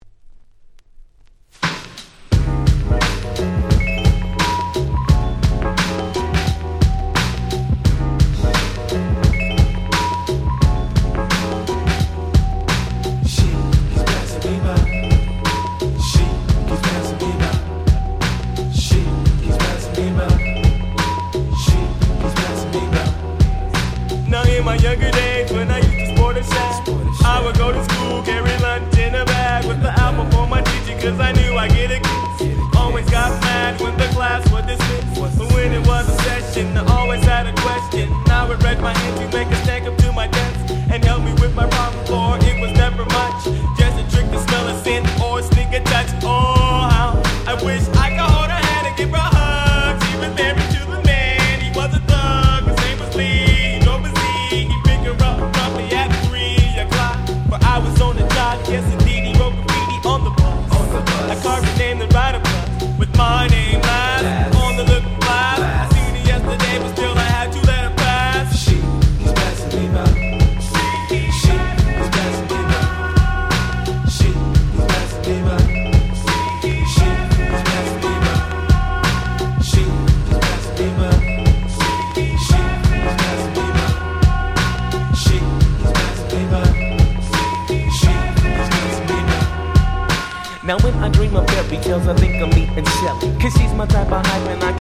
ファーサイド ジャジーヒップホップ Jazzy 90's Boom Bap ブーンバップ